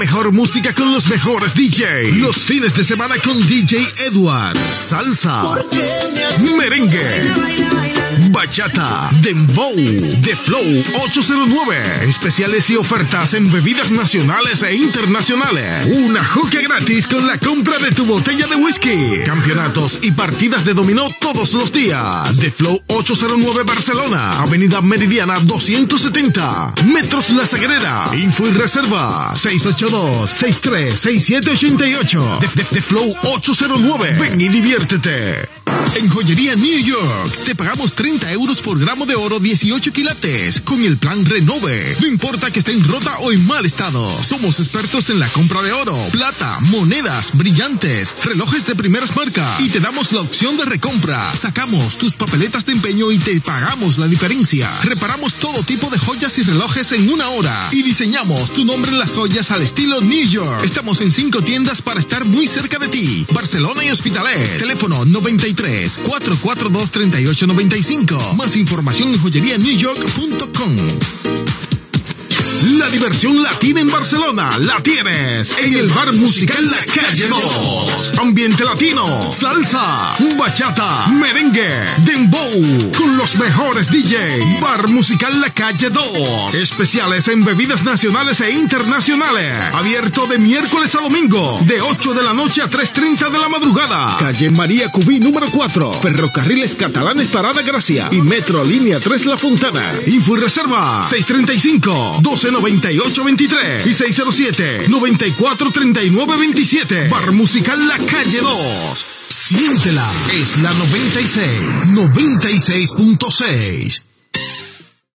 7e9399b9b5cc2e7ef5e60dd0bfaf62beecb240f8.mp3 Títol La 96 Emissora La 96 Titularitat Tercer sector Tercer sector Llatina Descripció Publicitat i indicatiu de l'emissora Anunciant The Flow.